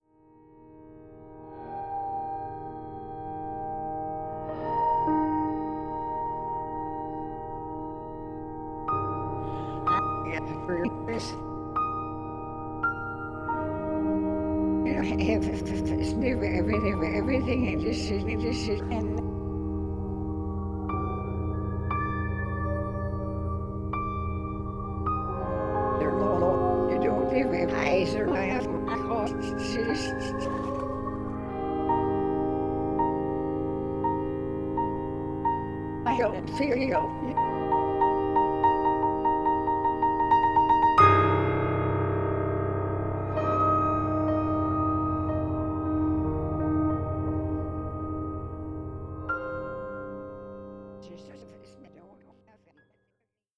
is for piano and electronics.
Old Cabell Hall at the University of Virginia